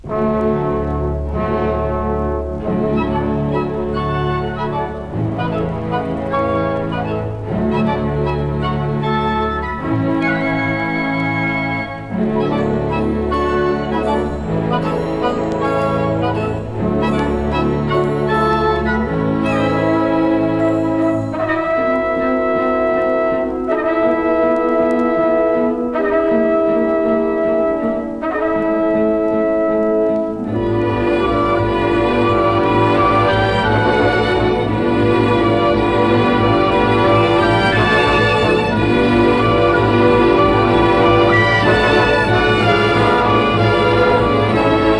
Jazz, Easy Listening, Lounge　USA　12inchレコード　33rpm　Stereo